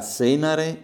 Villa Estense ~
All'orecchio, il suono mi pare più aspro.